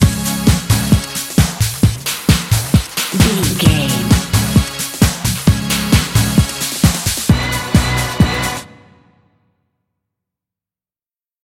Ionian/Major
Fast
synthesiser
drum machine
Eurodance